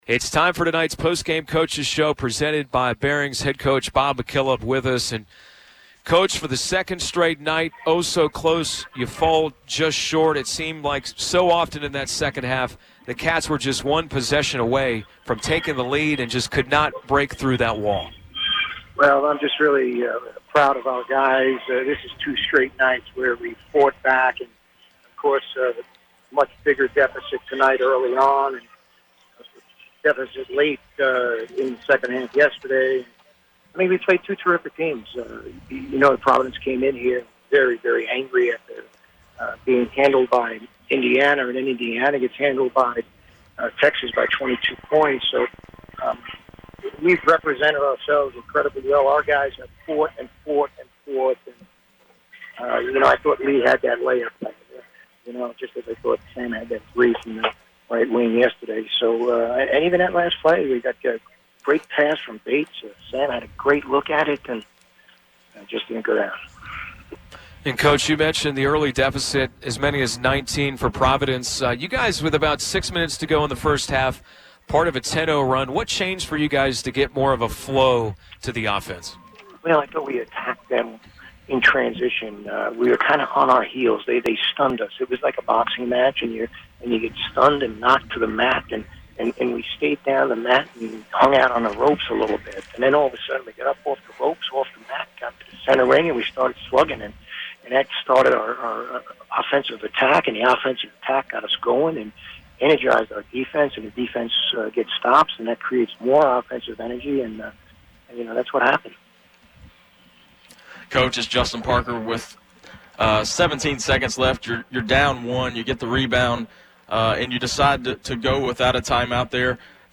Postgame Radio Interview
McKillop Post Game Providence.mp3